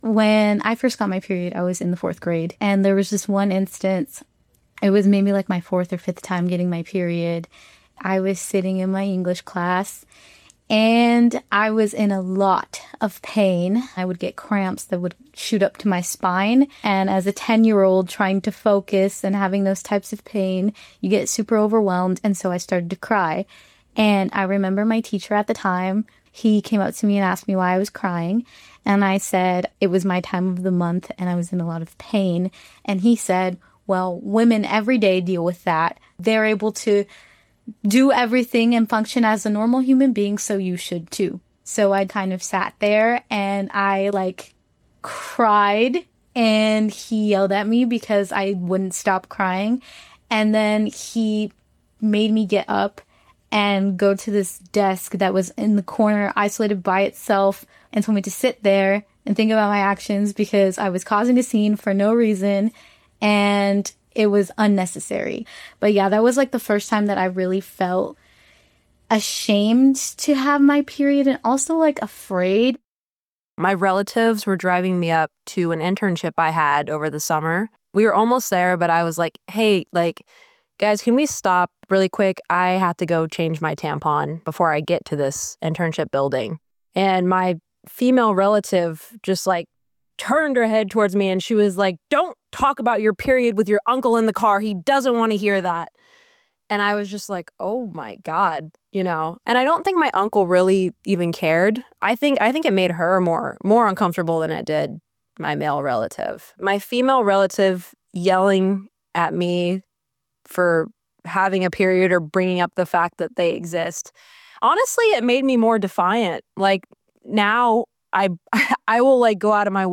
IPM Student Newsroom reporters interviewed young women in the Midwest about their experiences with menstrual shaming.